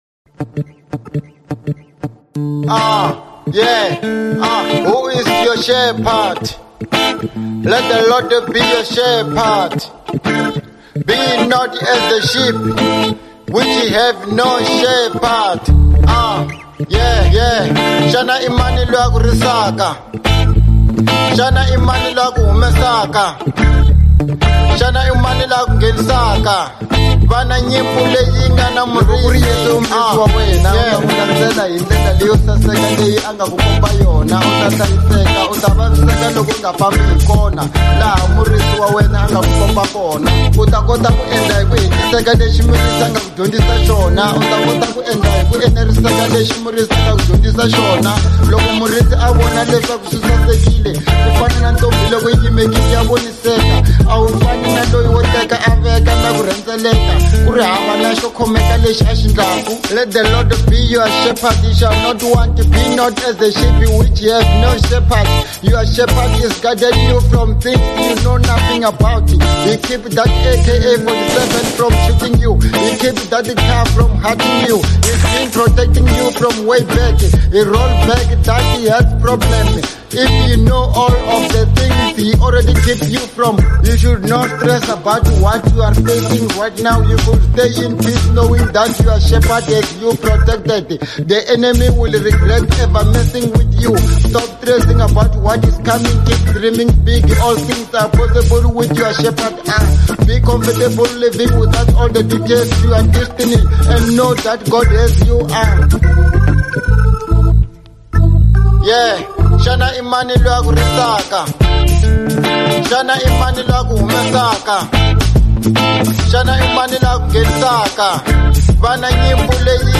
02:46 Genre : Hip Hop Size